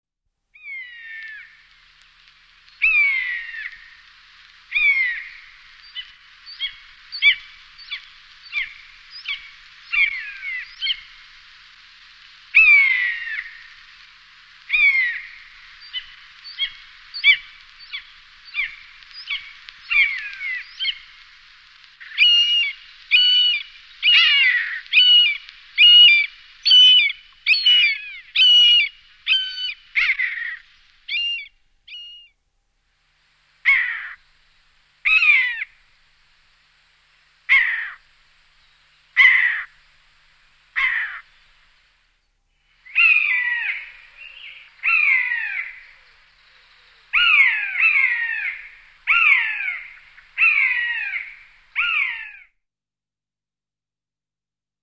Голоса птахів
U Канюк
buteo_buteo.MP3